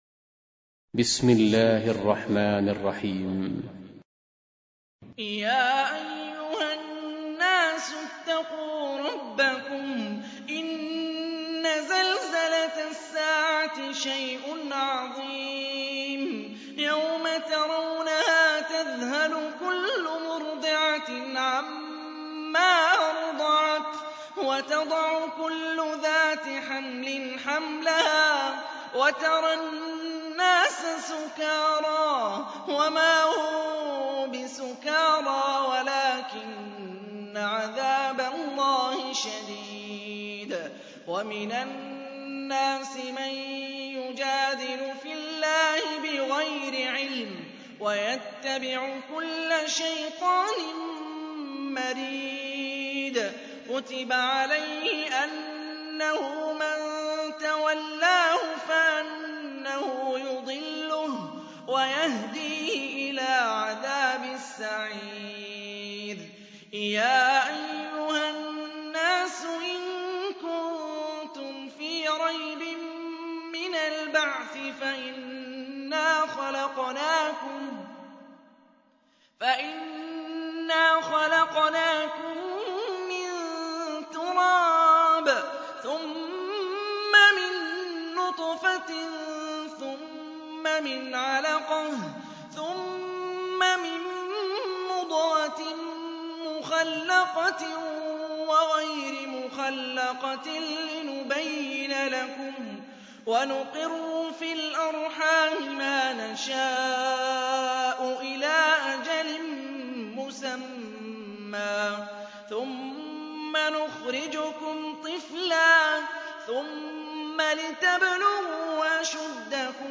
22. Surah Al-Hajj سورة الحج Audio Quran Tarteel Recitation
Surah Repeating تكرار السورة Download Surah حمّل السورة Reciting Murattalah Audio for 22. Surah Al-Hajj سورة الحج N.B *Surah Includes Al-Basmalah Reciters Sequents تتابع التلاوات Reciters Repeats تكرار التلاوات